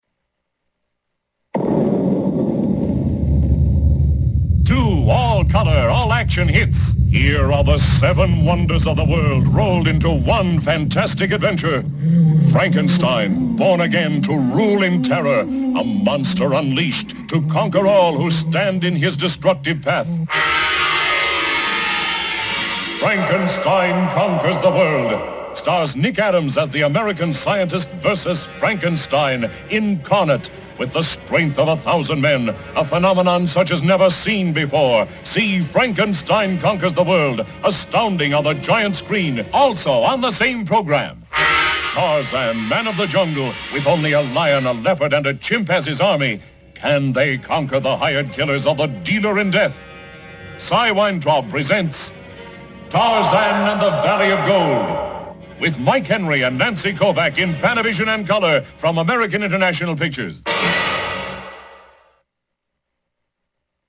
The spots are really good and the announcer brings excitement to the offerings.
And here’s the double bill radio spot.
Tarzan-Valley-of-Gold-double-bill-radio-spot-converted.mp3